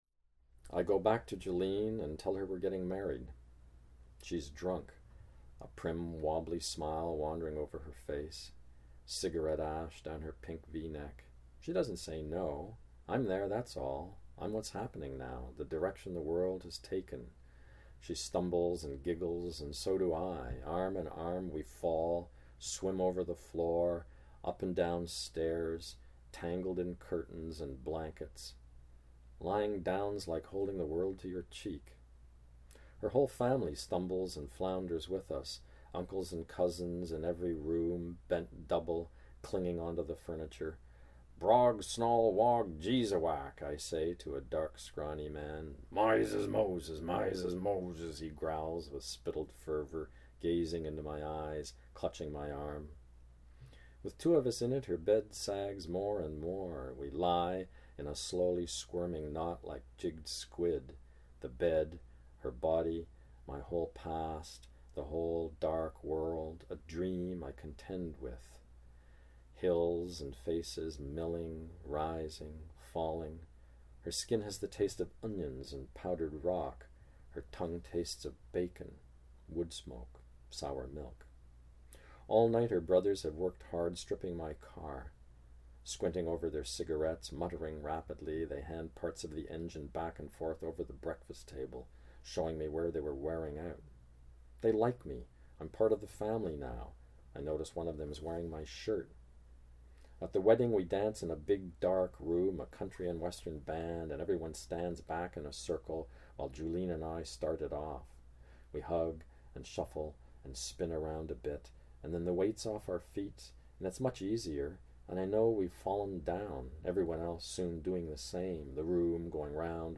John Steffler reads [I go back to Jewelleen] from The Grey Islands